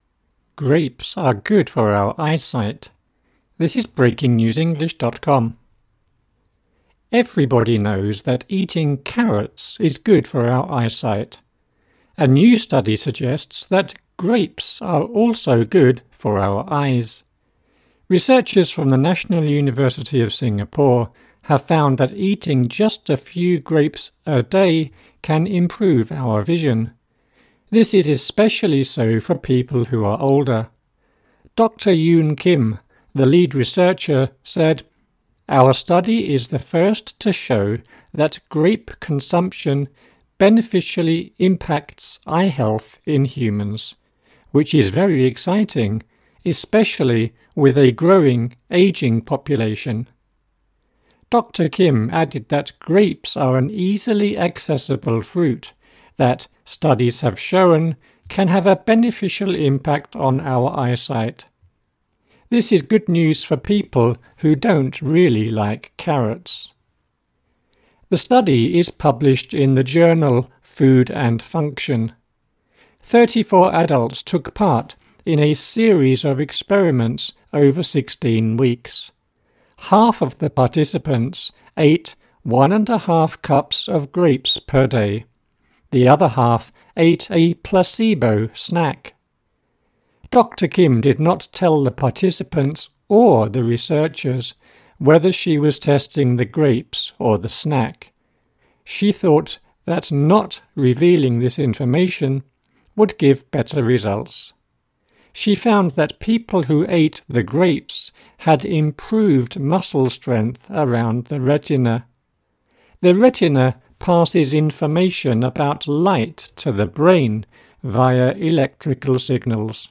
AUDIO(Slow)